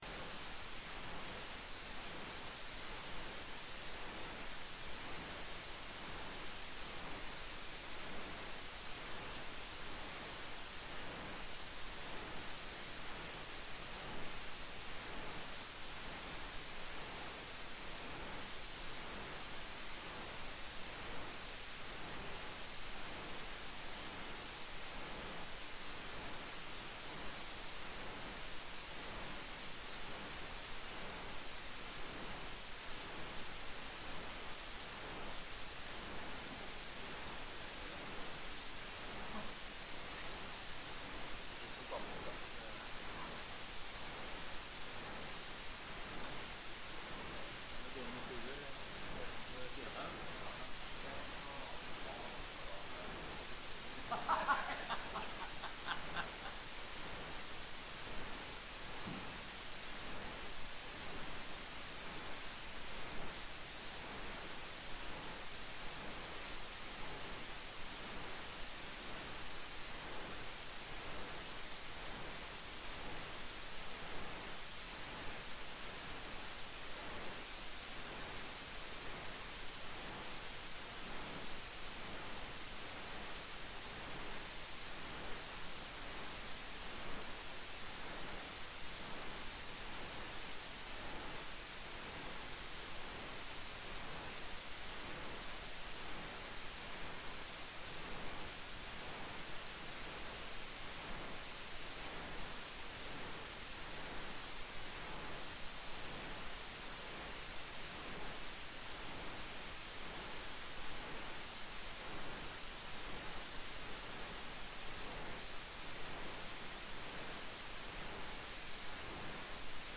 Forelesning 5.3.2020
Rom: Store Eureka, 2/3 Eureka